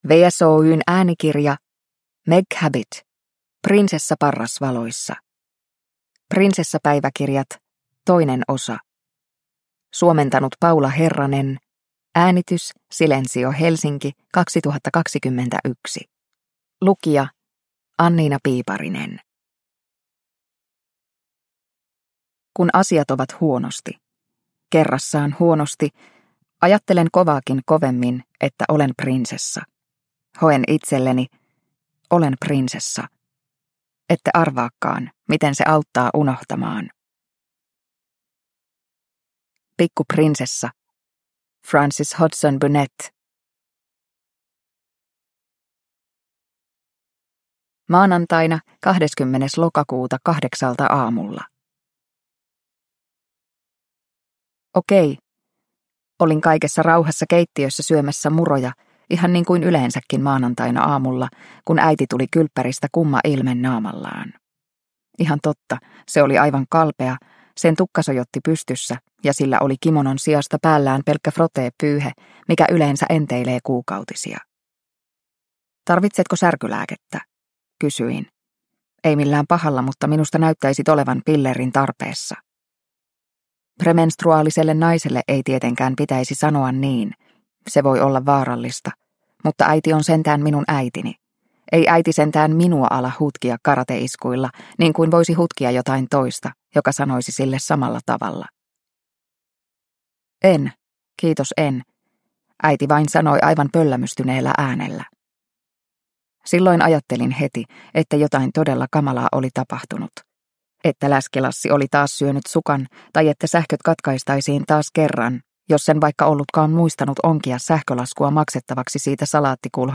Prinsessa parrasvaloissa – Ljudbok – Laddas ner